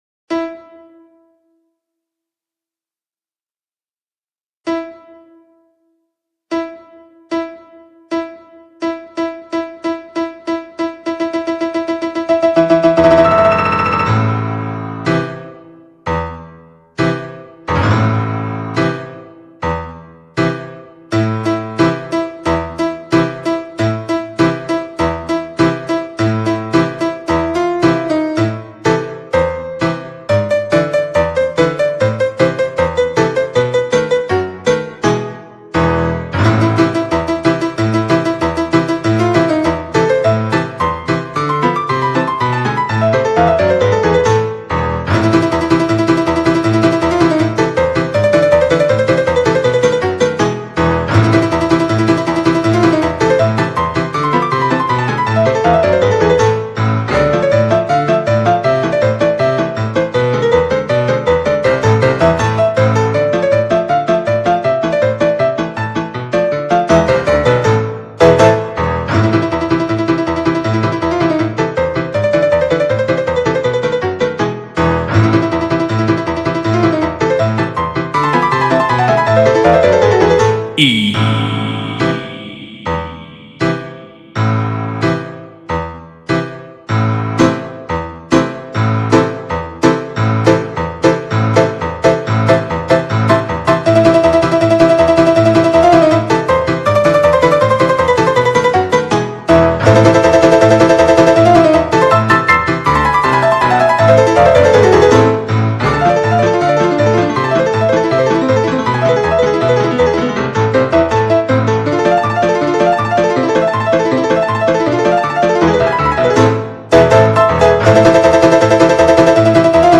BPM30-200
Audio QualityCut From Video